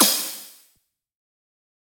taiko-soft-hitwhistle.ogg